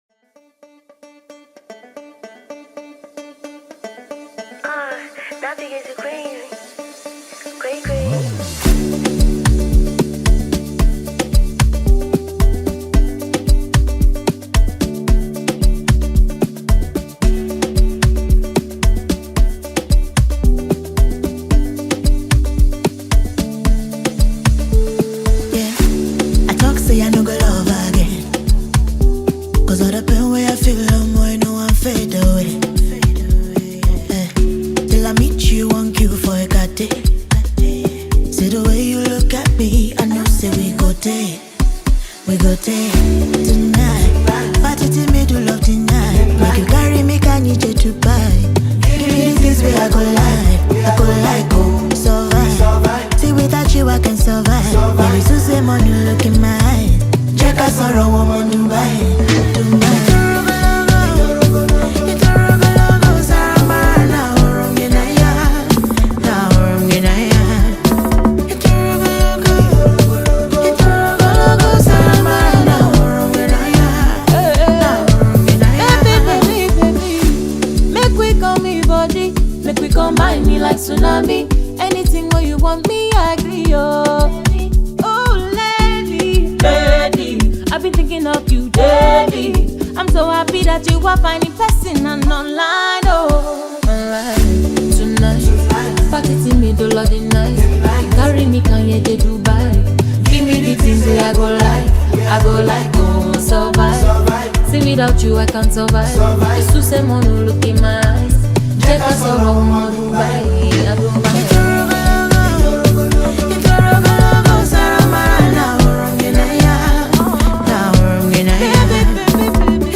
Known for his smooth vocals and infectious melodies